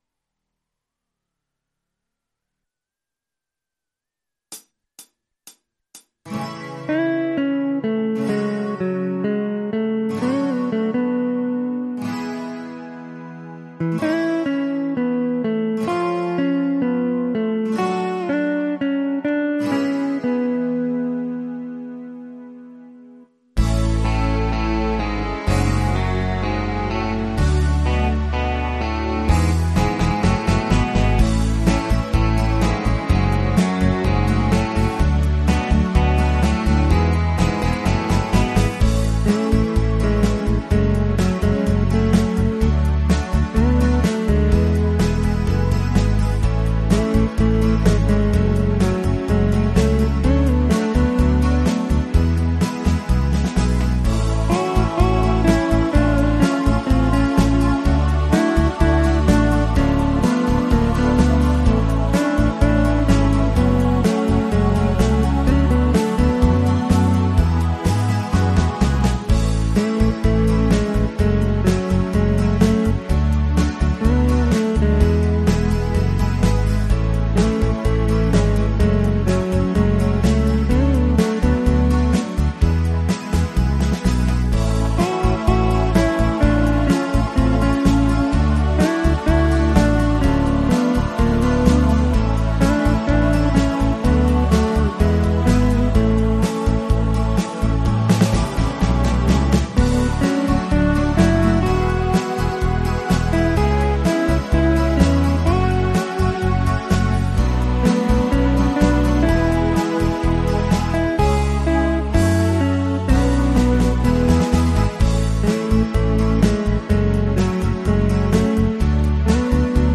la version instrumentale multipistes